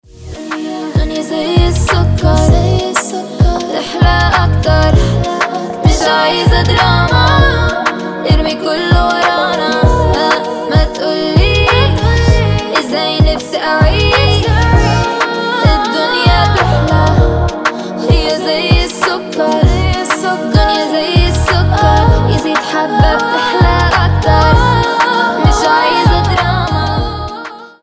• Качество: 320, Stereo
медленные
красивый женский голос
арабские
сладкие